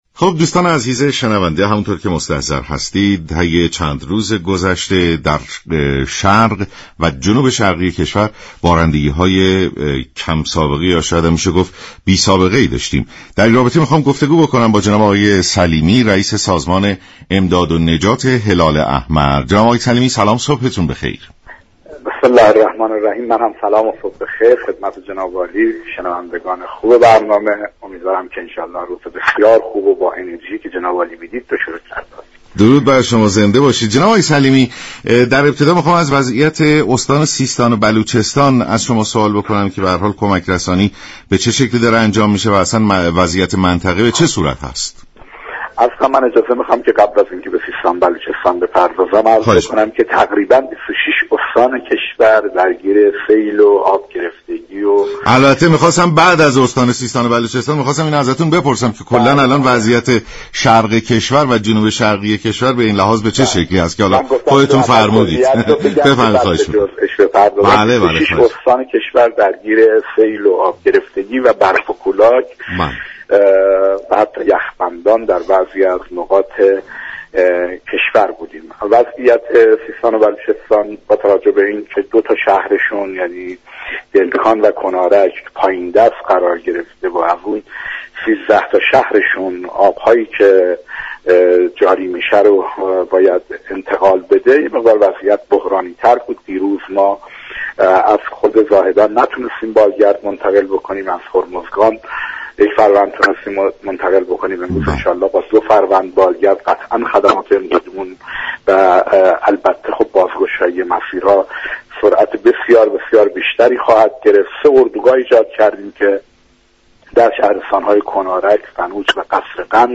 به گزارش شبكه رادیویی ایران، مرتضی سلیمی رییس سازمان امداد و نجات جمعیت هلال احمر در برنامه «سلام صبح بخیر» درباره سیل استان سیستان و بلوچستان گفت: روز گذشته ( یكشنبه بیست و دوم دی) سیل و آبگرفتگی بخش های زیادی از استان سیستان و بلوچستان را در برگرفت كه در این میان دو شهر كنارك و دلگان وضعیت بحرانی تری دارند.